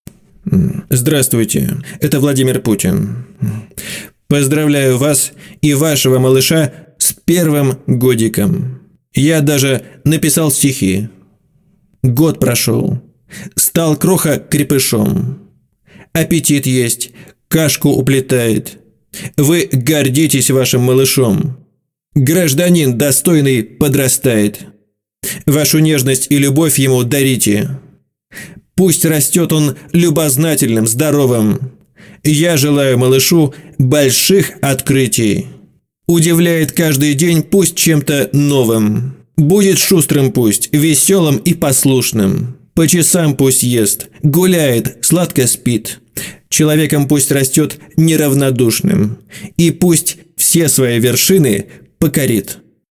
Поздравят бабушки, дедушки, крестные, близкие и дальние родственники, знакомые и соседи, но особым сюрпризом для родителей будет именно такое поздравление голосом президента с первым годиком ребенка.
Не смотря на то, что будет понятно, что это голосовое поздравление просто шутка, но впечатление останется сильное.